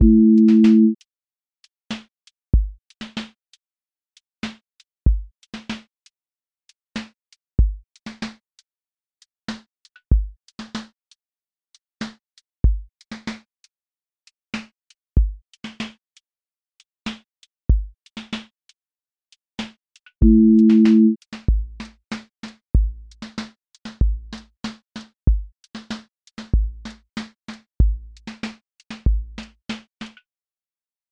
neo-soul live lilt pocket
• voice_kick_808
• voice_snare_boom_bap
• voice_hat_rimshot
• voice_sub_pulse
• tone_warm_body
• motion_drift_slow